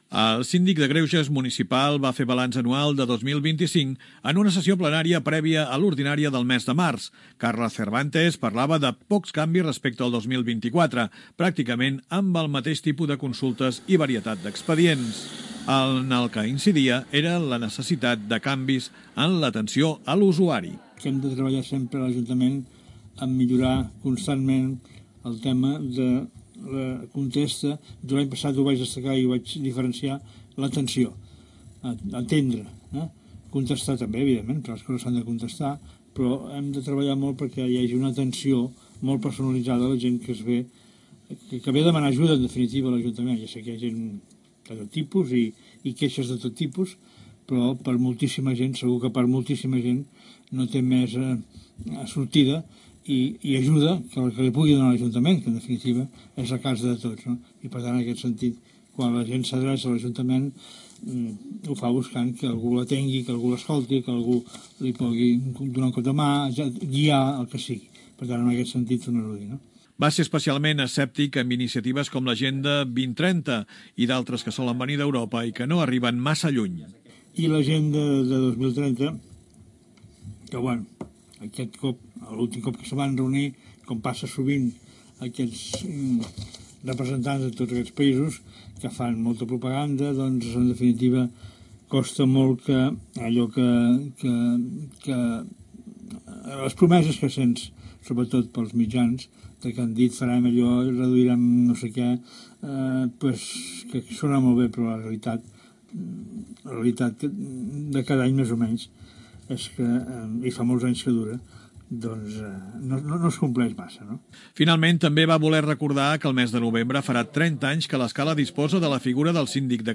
El Síndic de greuges municipal va fer el balanç anual de 2025 en una sessió plenària prèvia a la ordinària del mes de març.